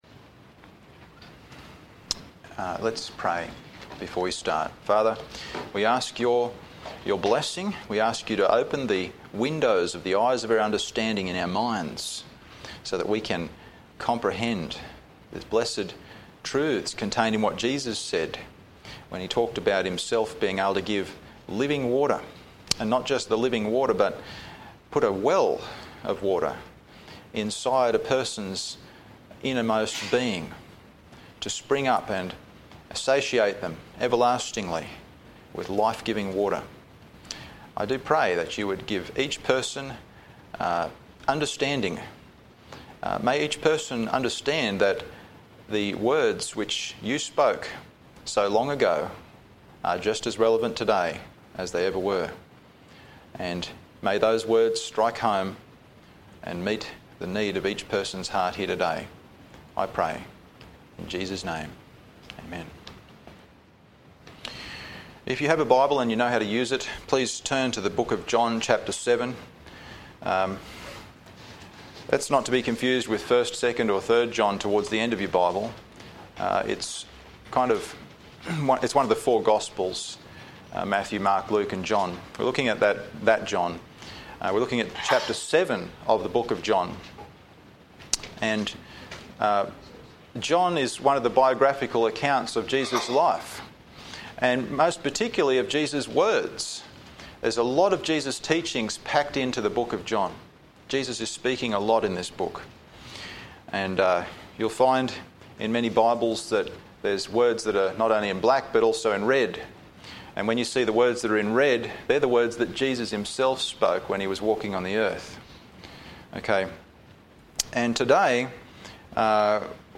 Passage: John 7:37-38 Service Type: Sunday Morning